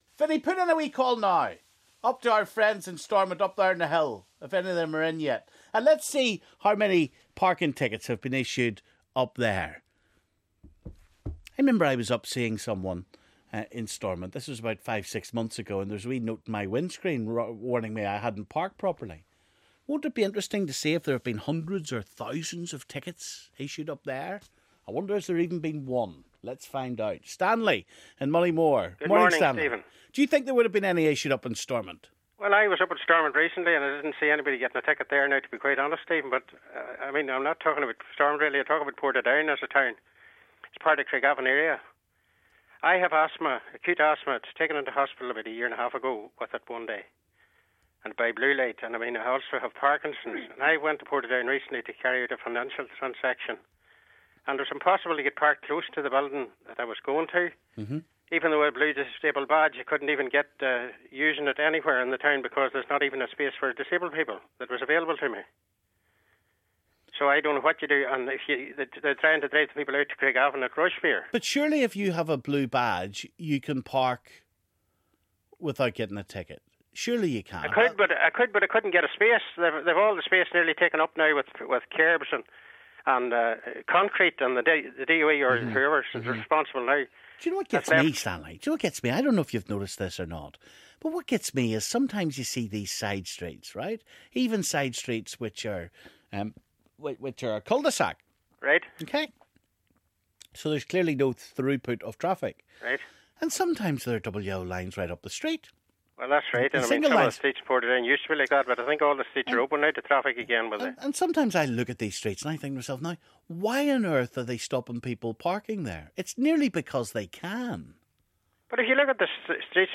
Nolan callers react to the levels of parking fines across Northern Ireland